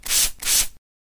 喷杀虫剂.mp3